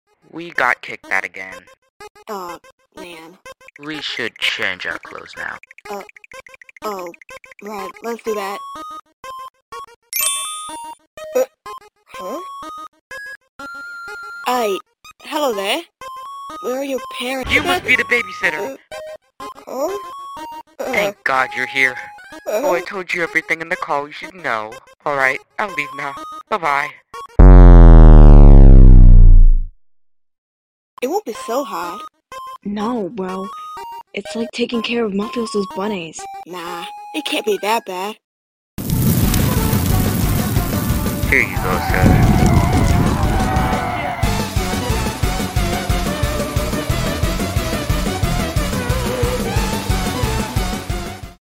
mafia liags (forsaken comic dub) sound effects free download